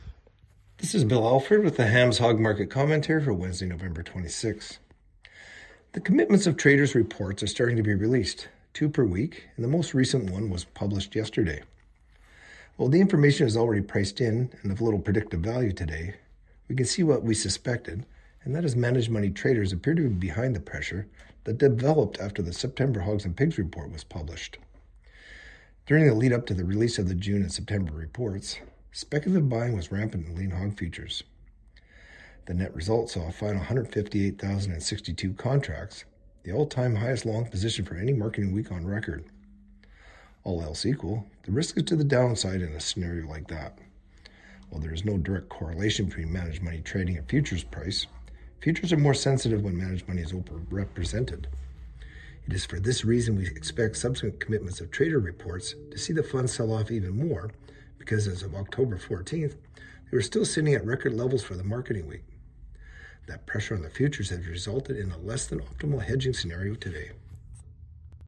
Hog-Market-Commentary-Nov.-26-25.mp3